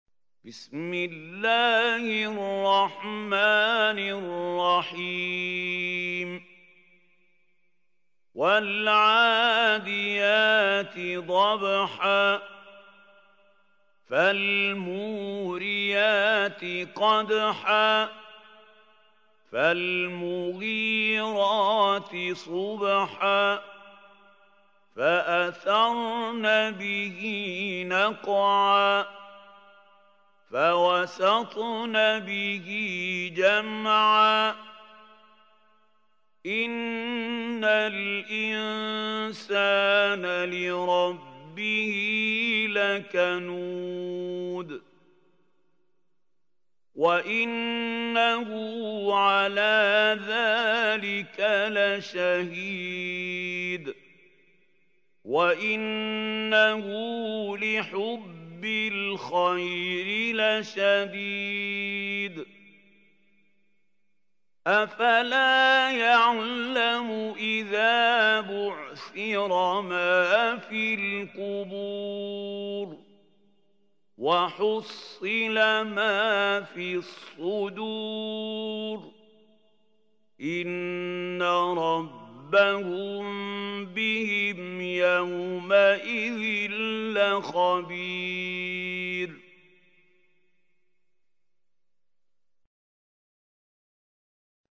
سورة العاديات | القارئ محمود خليل الحصري